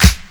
Original creative-commons licensed sounds for DJ's and music producers, recorded with high quality studio microphones.
00s Original Funk Clap Single Shot G Key 01.wav
Royality free clap one shot - kick tuned to the G note. Loudest frequency: 2855Hz
00s-original-funk-clap-single-shot-g-key-01-D5Y.wav